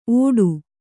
♪ ōḍu